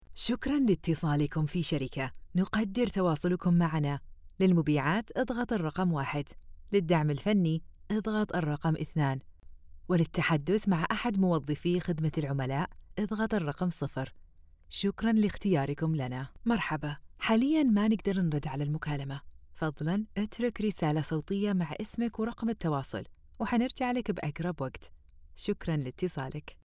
Tief, Natürlich, Unverwechselbar
Telefonie